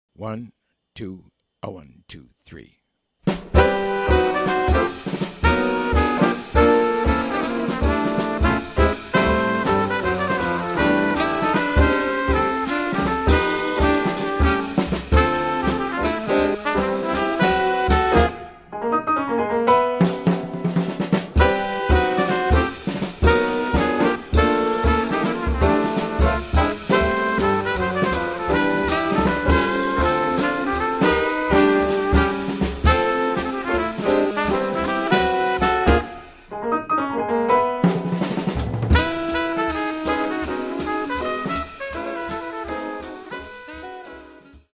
A harmonic workout
quintet recording